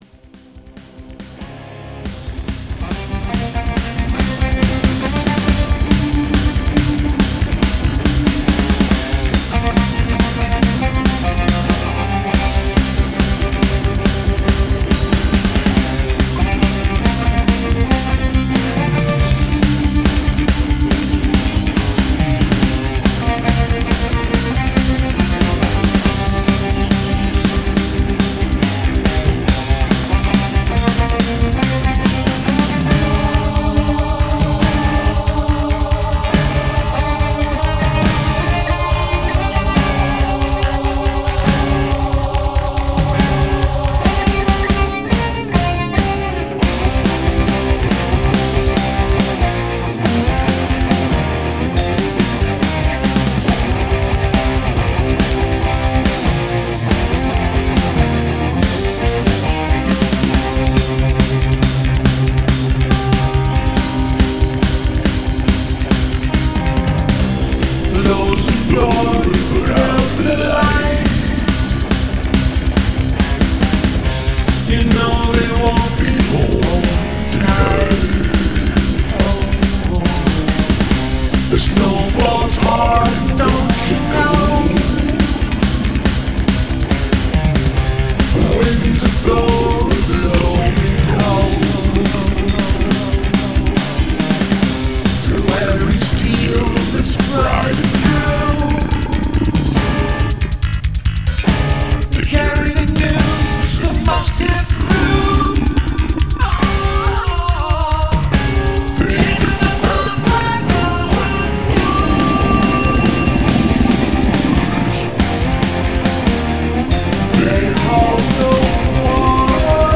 made an upbeat metal version of it
To my ears, the drums sound like a drum machine.